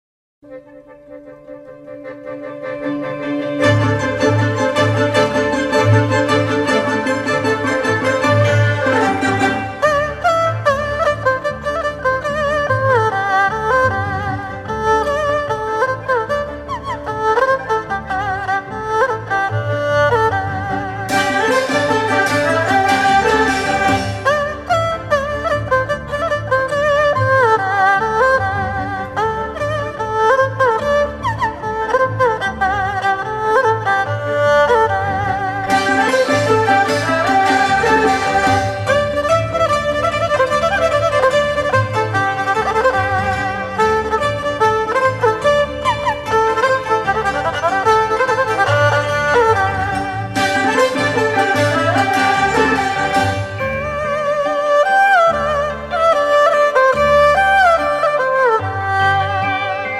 民族管弦乐：丢丢铜仔